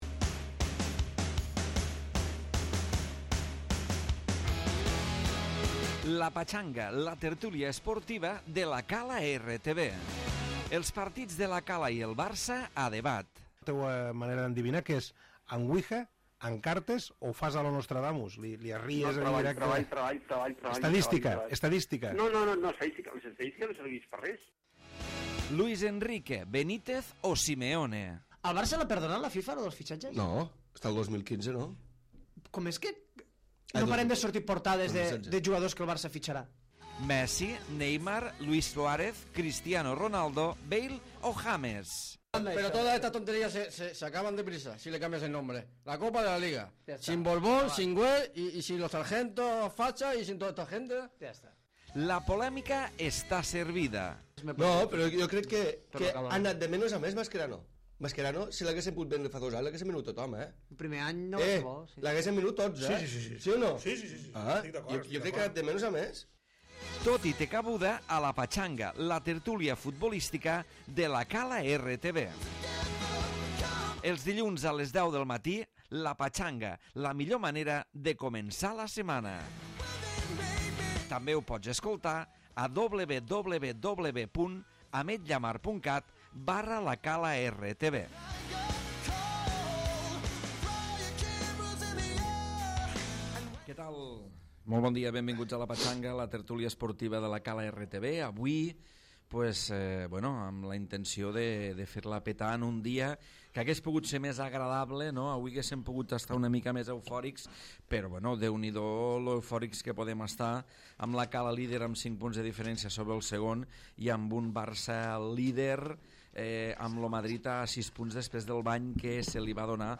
Tertúlia futbolística d'actualitat, avui centrada en la victòria del Barça sobre el Madrid al Bernabeu.